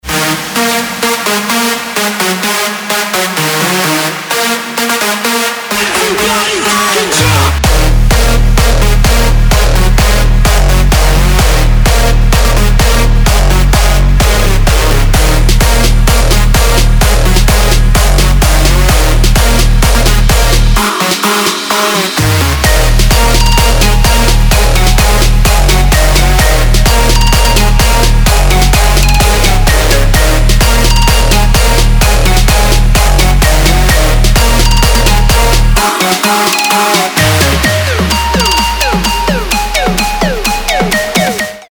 громкие
крутые
качающие
Big Room
electro house
Клубный микс